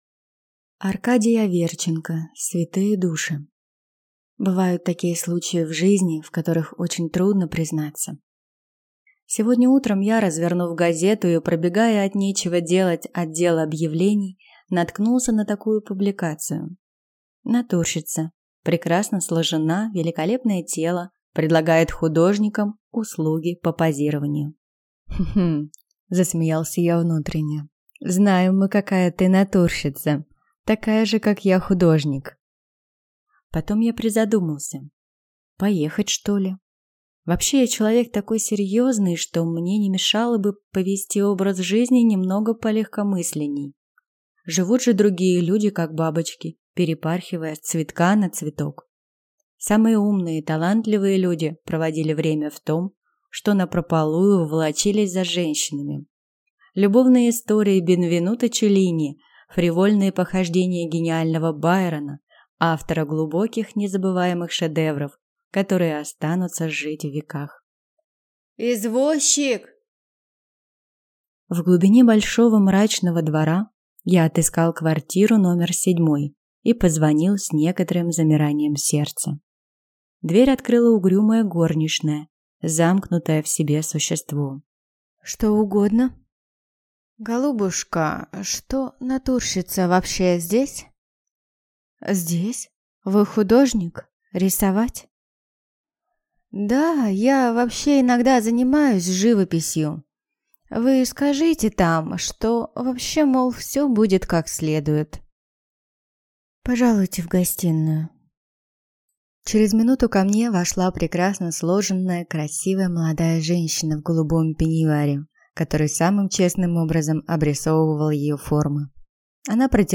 Аудиокнига Святые души | Библиотека аудиокниг